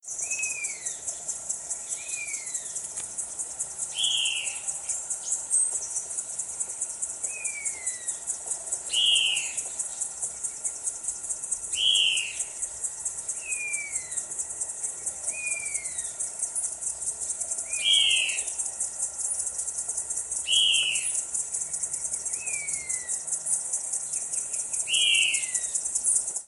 Buff-bellied Puffbird (Notharchus swainsoni)
Superpuesto a Fiofio copetón y muy de fondo llamado de Tiluchi ala rojiza.
Detailed location: Selva Iryapú (600 hectáreas)
Condition: Wild
Certainty: Recorded vocal
FiofioCopeton_CHACURU_GRANDE_LLAMADOS_3138.mp3